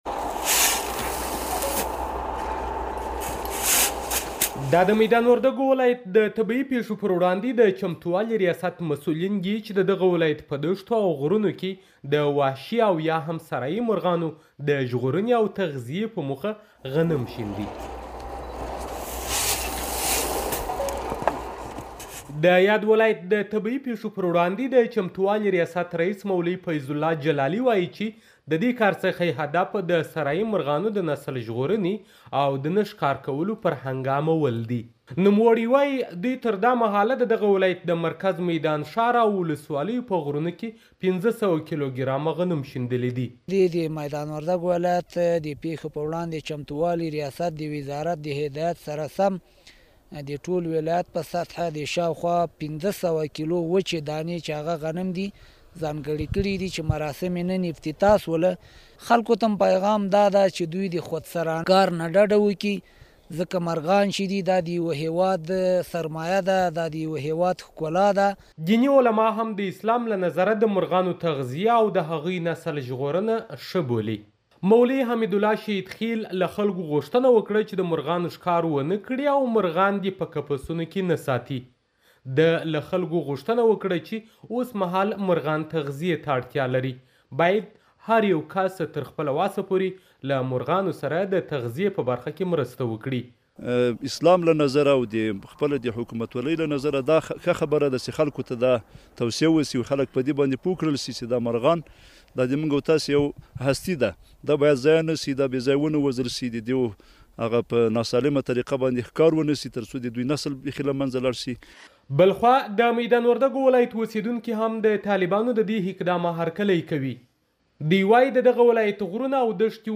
د ميدان وردګ راپور